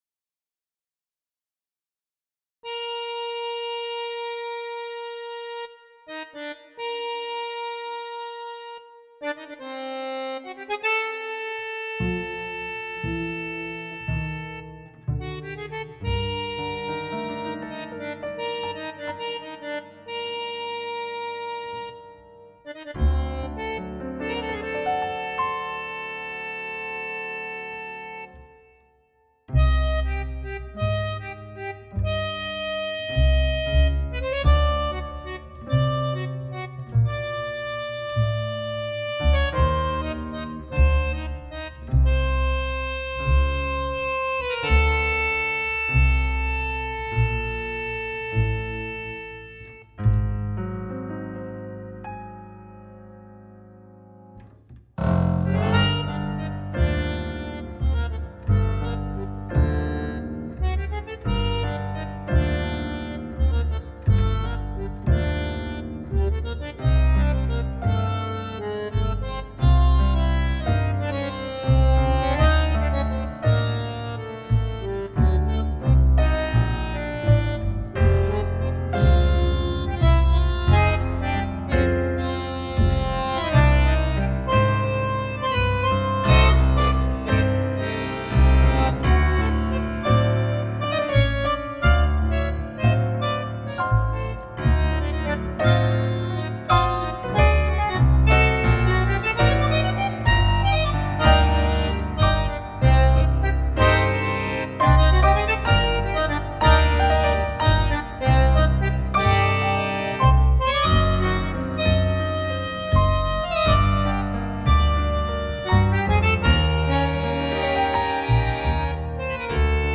танго для бандонеона, фно и контрабаса
Хочется услышать мнения о самой композиции, ее форме, инструментальных партиях. Запись сделана с использованием семплов, микс тоже демонстрационный.